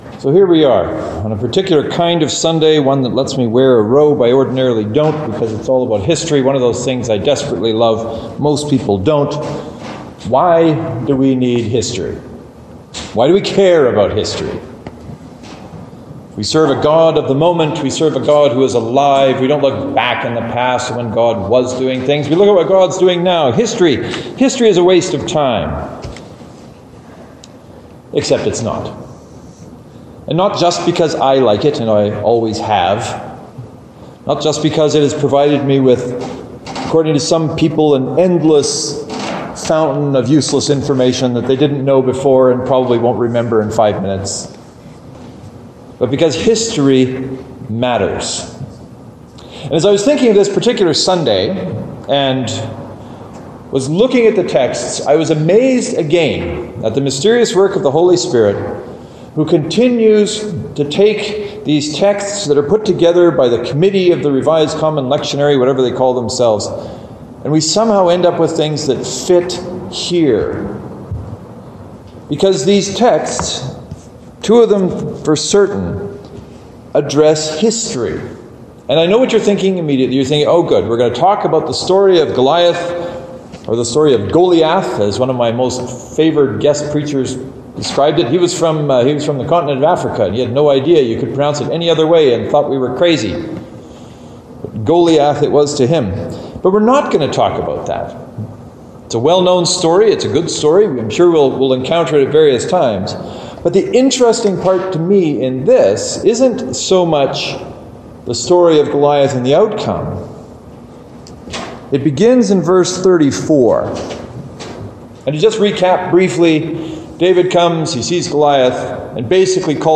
The two sermons makes it pretty clear I love history.